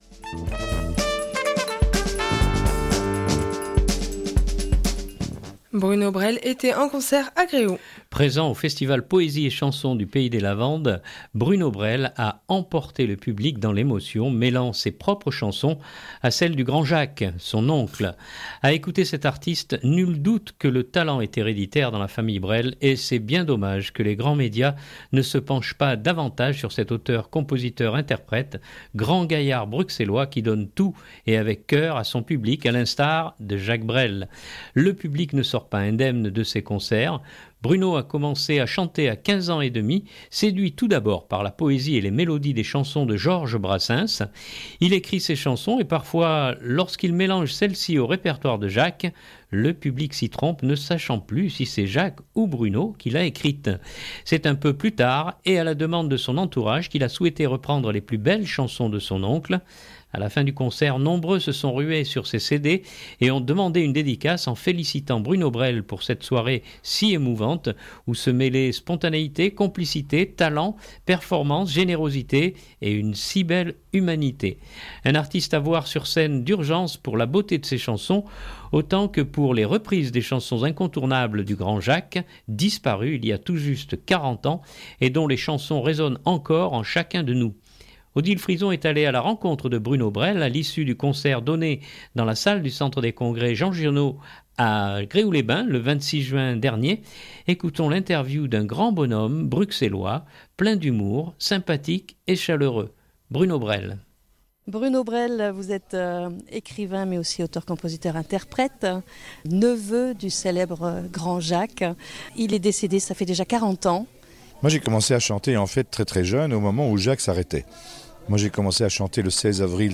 Ecoutons l’interview d’un grand bonhomme bruxellois, plein d’humour, sympathique et chaleureux.